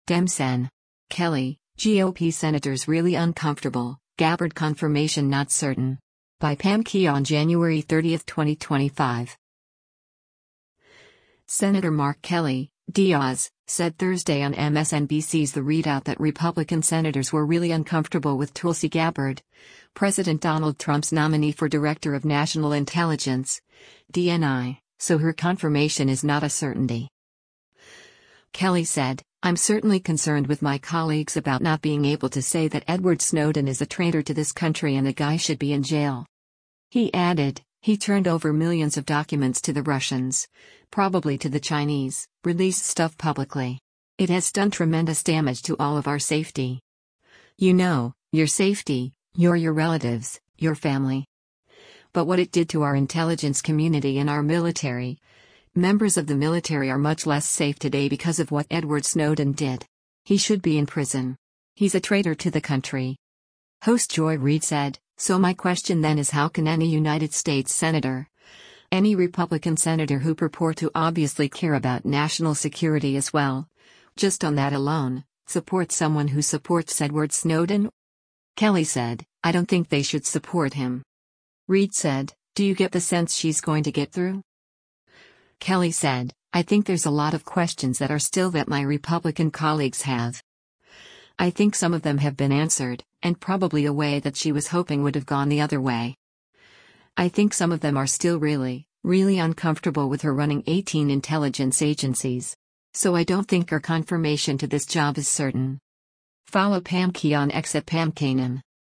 Senator Mark Kelly (D-AZ) said Thursday on MSNBC’s “The ReidOut” that Republican senators were “really uncomfortable” with Tulsi Gabbard, President Donald Trump’s nominee for Director of National Intelligence (DNI), so her confirmation is not a certainty.
Host Joy Reid said, “So my question then is how can any United States Senator, any Republican Senator who purport to obviously care about national security as well, just on that alone, support someone who supports Edward Snowden?”